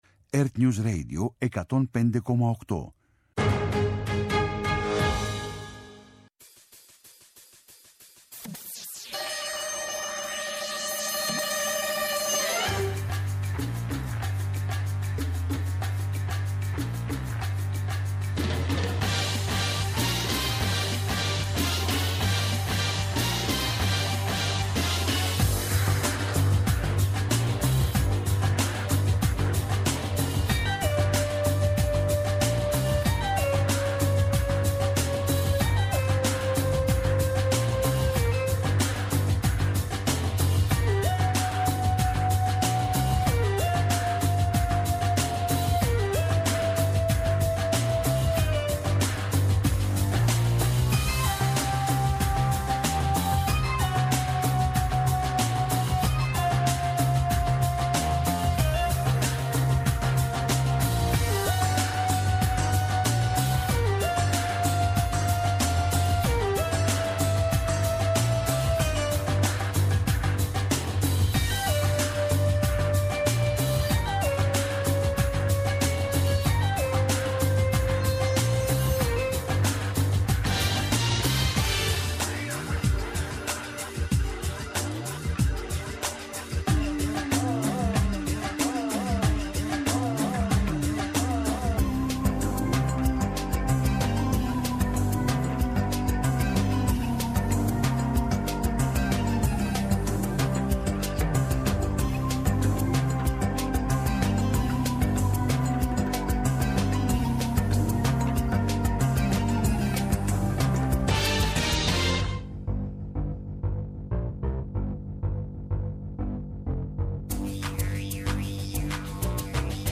Στις «Συναντήσεις» απόψε ο Απόστολος Καρανίκας, Αντιδήμαρχος του Δήμου Παλλήνης, αρμόδιος για την προστασία και ευζωία των ζώων, είναι εξειδικευμένος εκπαιδευτής σκύλων καιπραγματοποιεί ελέγχους στο πλαίσιο της προστασίας των ζώων. Με αφορμή την Παγκόσμια Ημέρα Ζώων στις 4 Οκτωβρίου, μιλάμε για τη φροντίδα και την αξία της καθημερινής προσοχής στις ανάγκες τους.